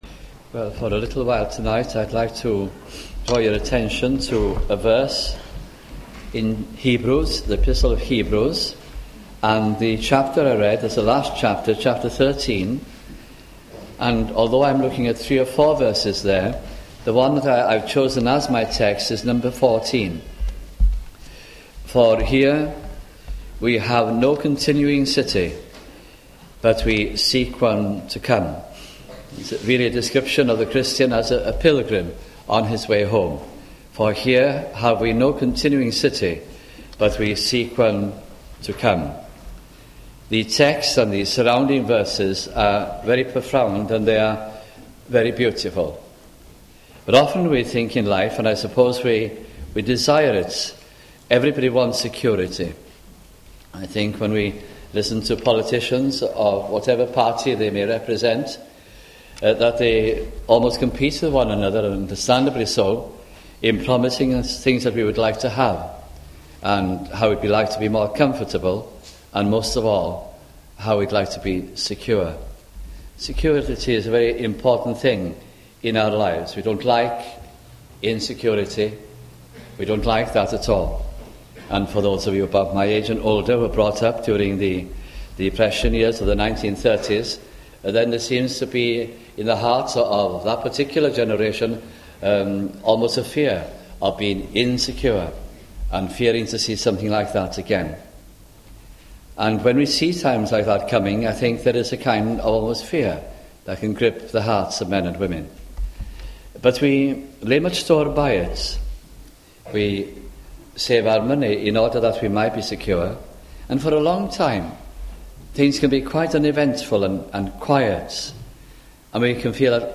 » Hebrews Gospel Sermons